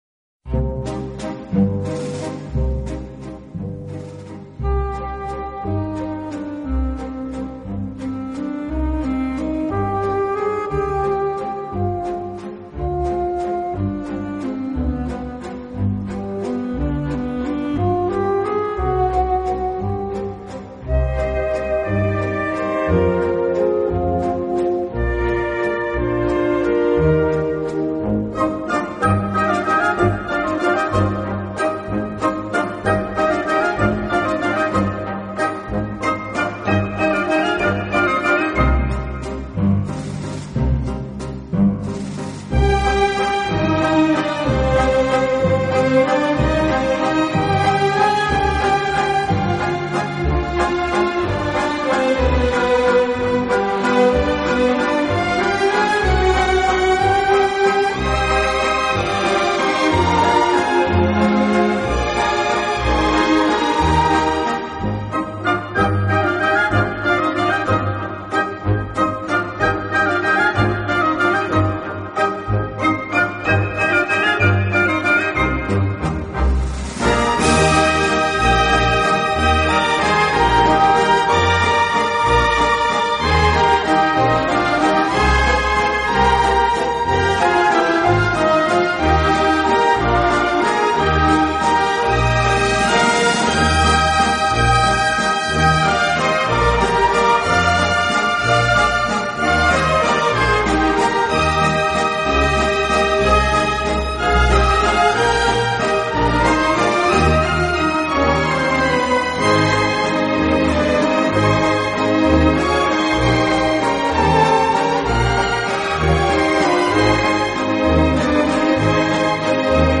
【小提琴专辑】
专辑语言：纯音乐
他和自己的小型管弦乐队默契配合，将古典音乐与大众音乐进行了有机的融合，